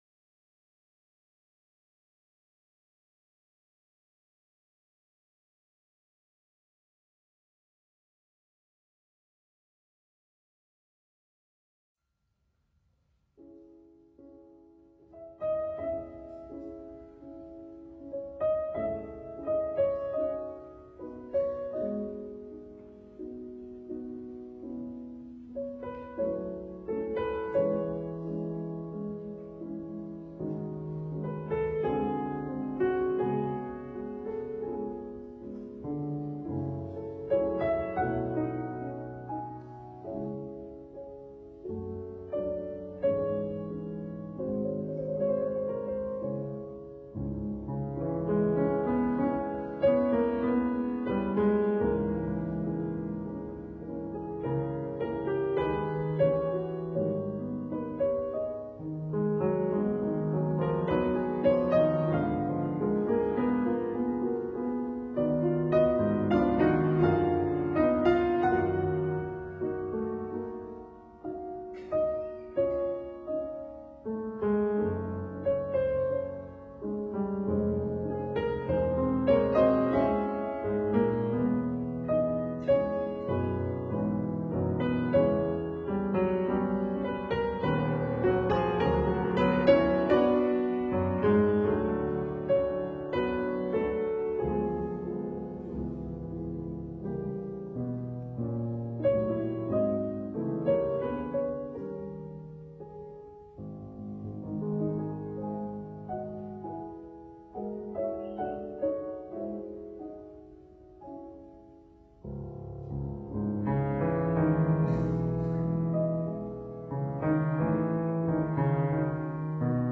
刻骨铭心的唯美爱情